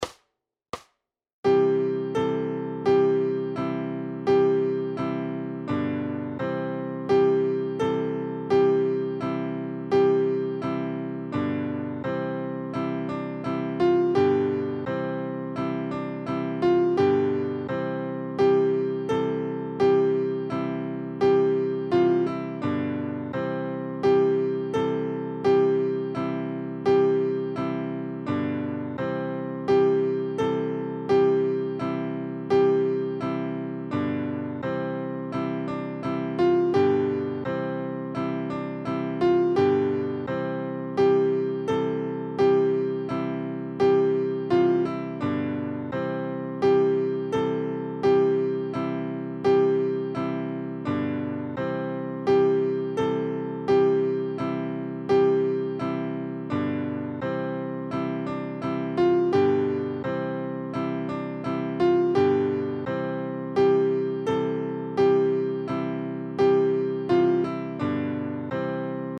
Koledy na 2 akordy
Aranžmá Noty na snadný sólo klavír
Hudební žánr Vánoční koledy